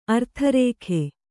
♪ artharēkhe